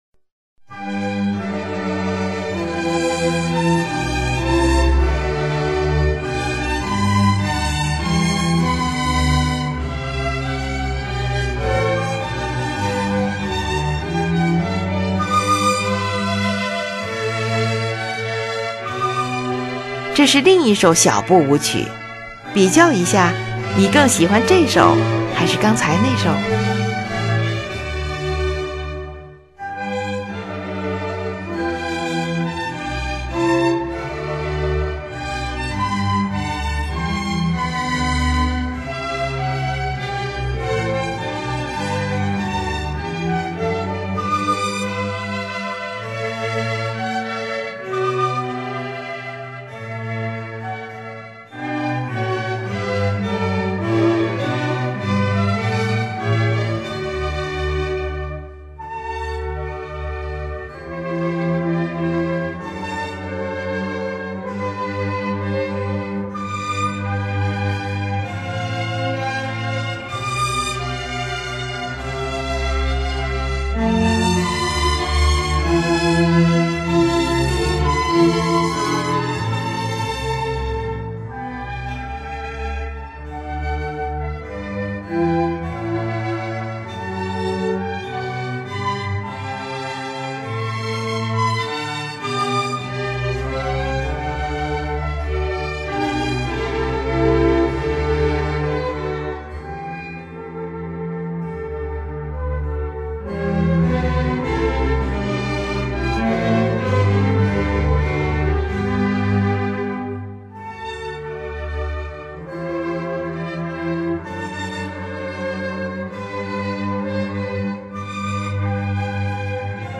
乐章。乐器使用了小提琴、低音提琴、日耳曼横笛、法兰西横笛、双簧管、圆号、小号等